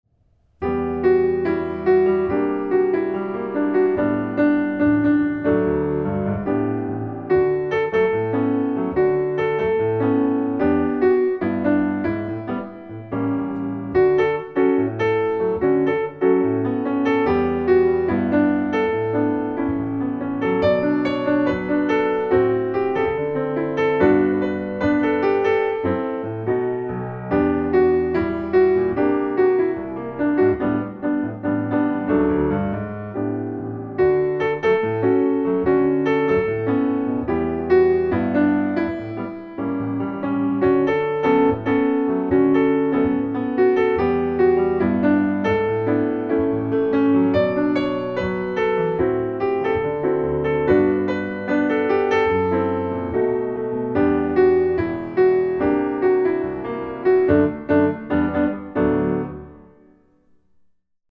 solsikke_piano-melodi.mp3